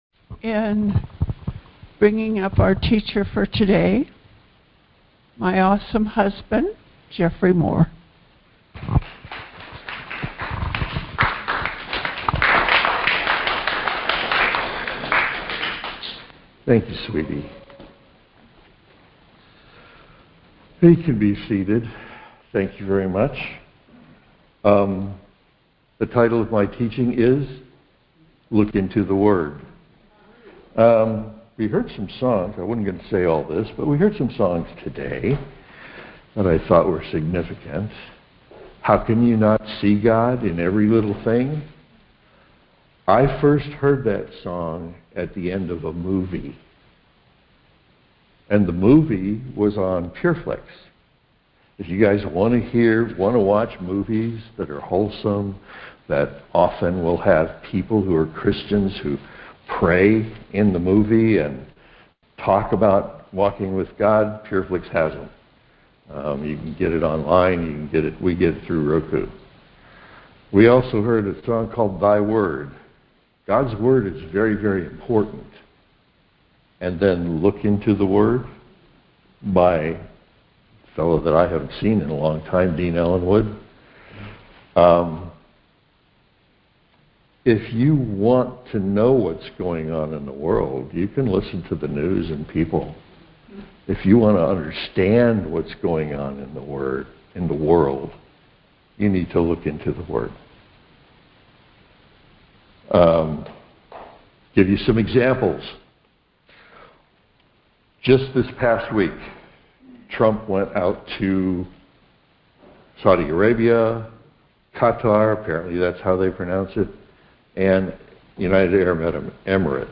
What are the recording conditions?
Details Series: Conference Call Fellowship Date: Sunday, 18 May 2025 Hits: 349 Scripture: John 17:17 Play the sermon Download Audio ( 18.52 MB )